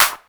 Snare_04.wav